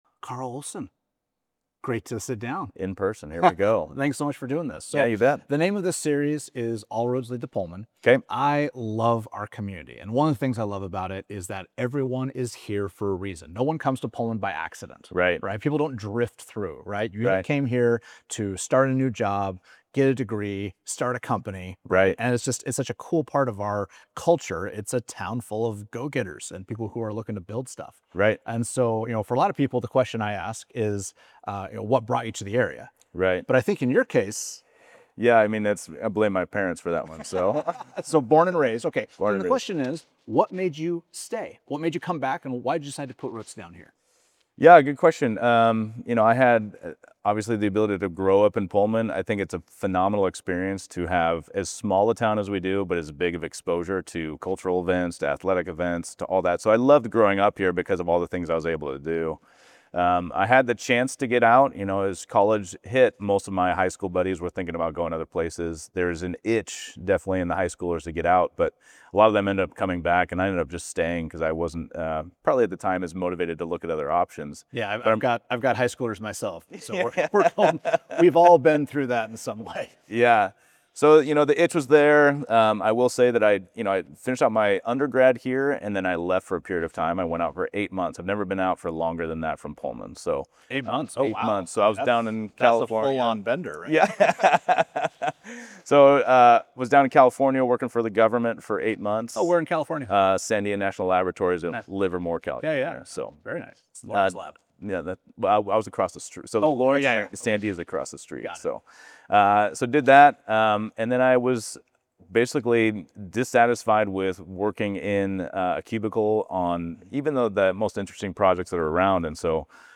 I sat down with him to discuss why he is running for city council, how to restore confidence in the community, and his views on issues related to local property owners.